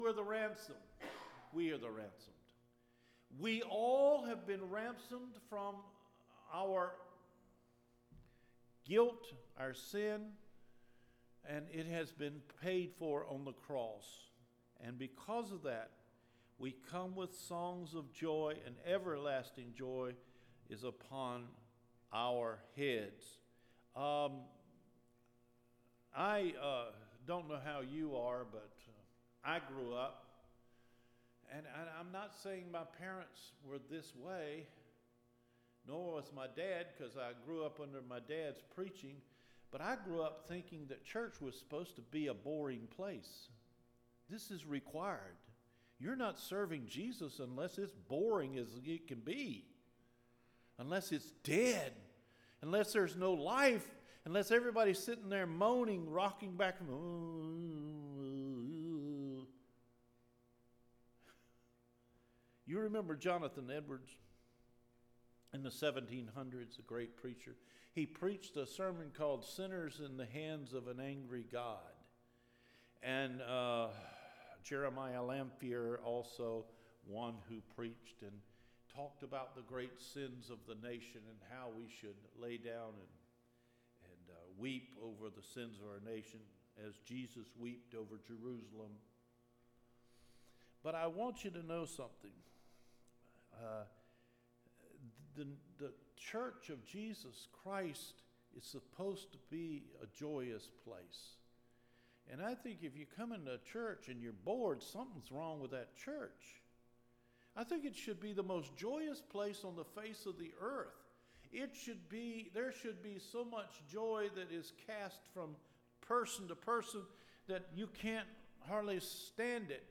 JANUARY 10 SERMON – RISE UP IN JOY, THIS IS GOD’S WILL FOR YOU – Cedar Fork Baptist Church